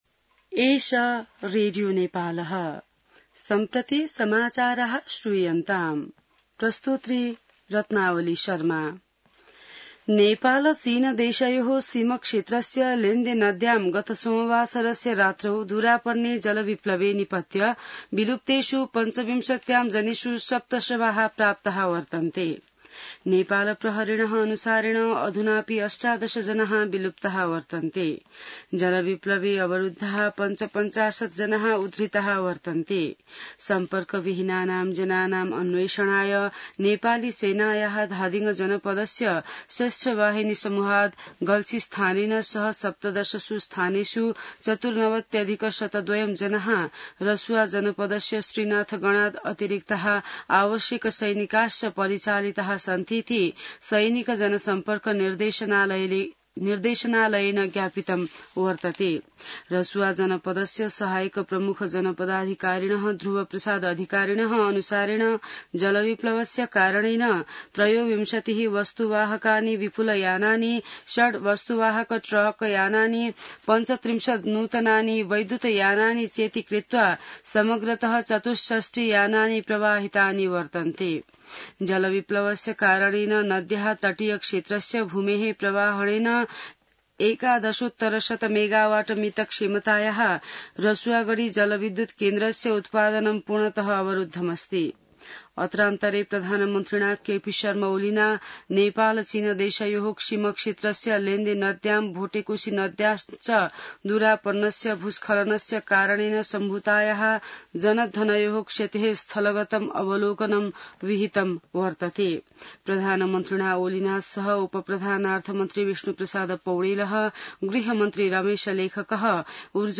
संस्कृत समाचार : २५ असार , २०८२